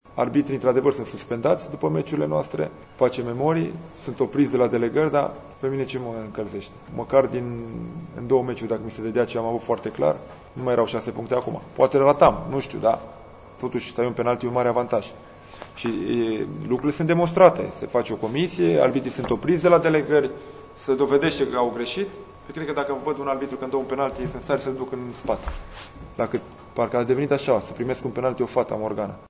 Pentru a asculta declarațiil antrenorului Dan Alexa, asționați tasta „play”: